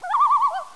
Sound Effects
collide.wav